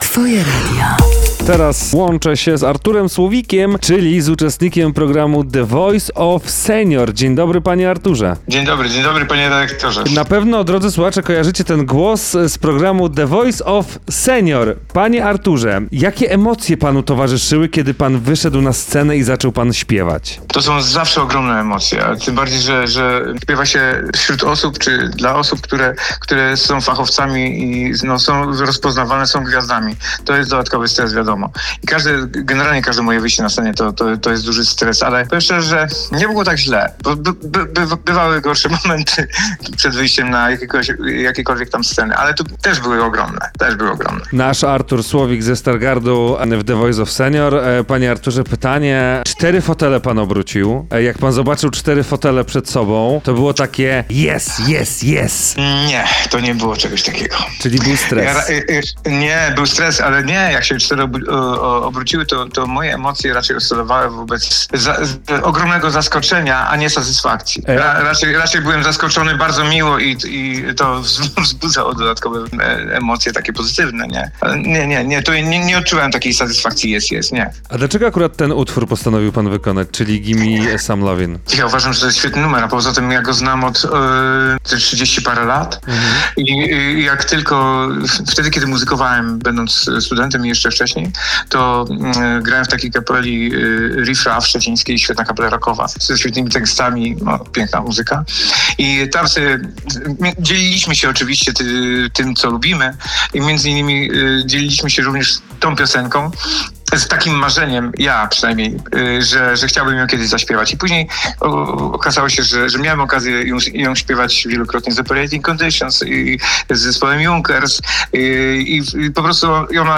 W rozmowie
na antenie Twojego Radia